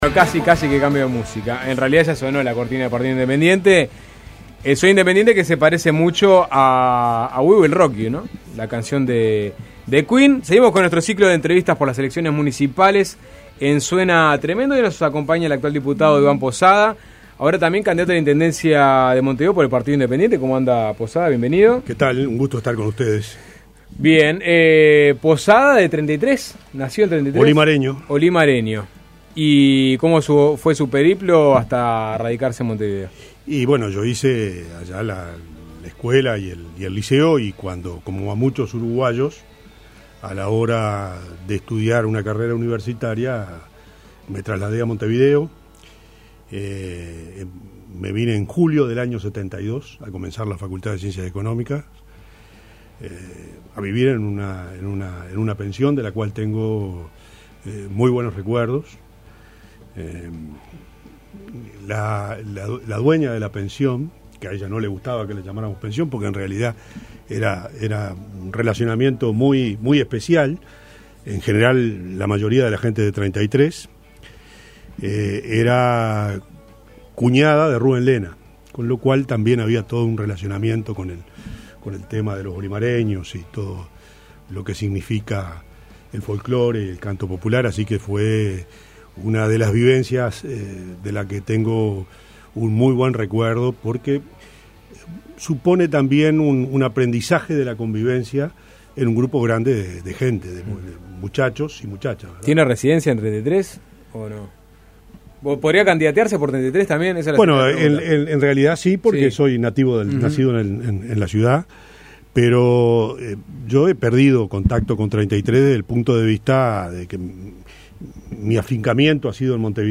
Conversamos con el candidato independiente a la Intendencia de Montevideo, Iván Posada. Criticó el proceso de descentralización y explicó la propuesta de los independientes de crear una Guardia Civil.